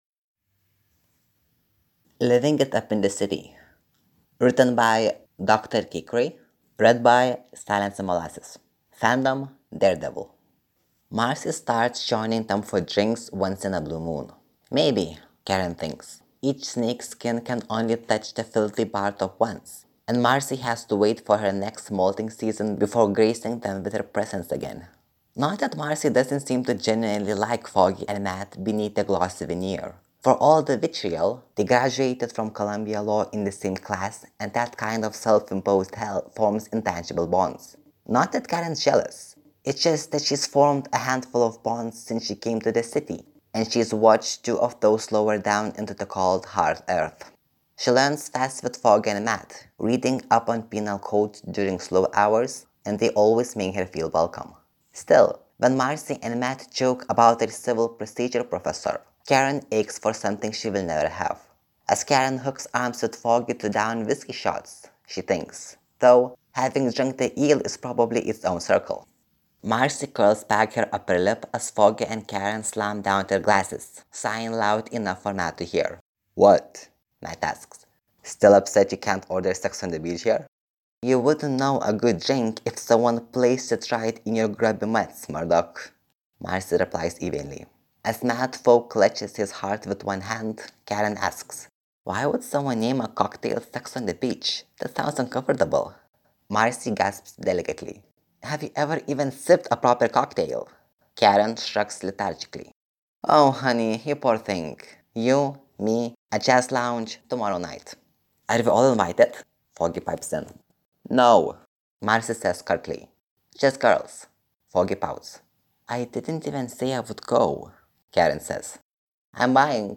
[Podfic] Living it up in the city, daredevil (tv) fanfic | FanfictionBook